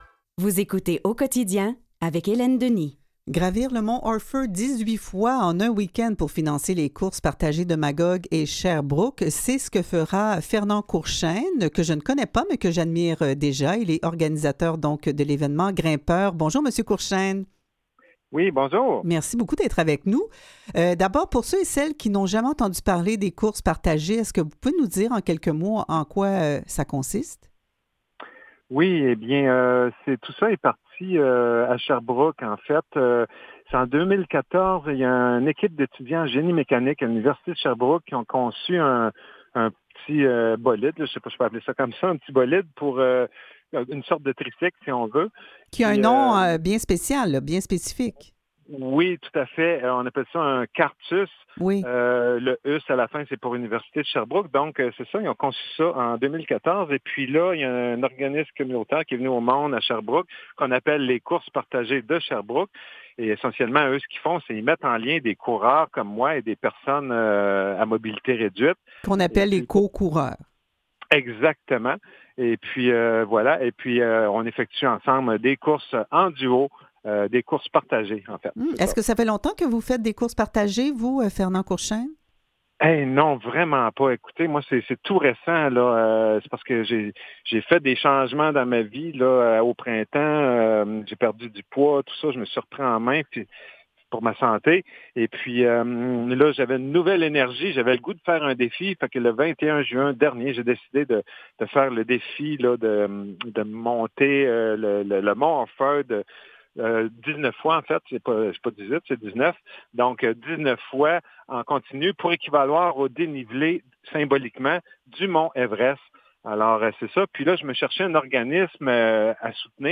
Aux Quotidiens Revue de presse et entrevues du 9 septembre